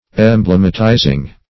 Search Result for " emblematizing" : The Collaborative International Dictionary of English v.0.48: Emblematize \Em*blem"a*tize\, v. t. [imp.
emblematizing.mp3